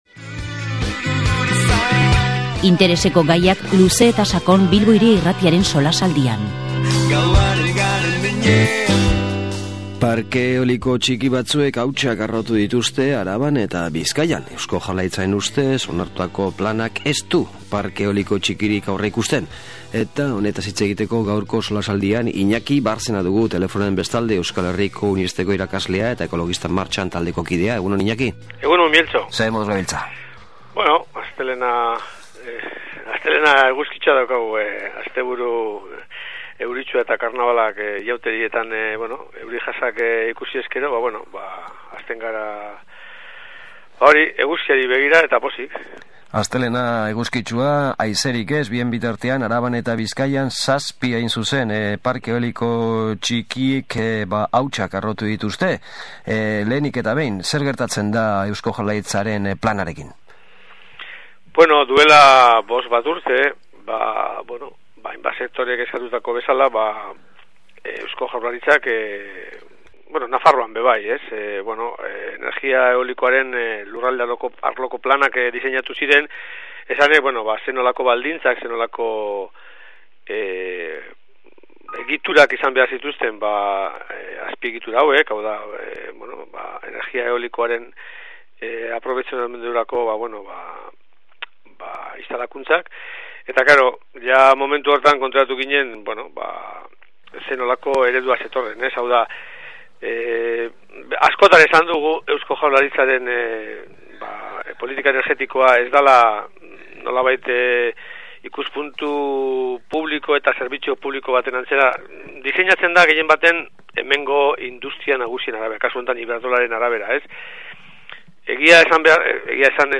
SOLASALDIA: Parke Eoliko txikiak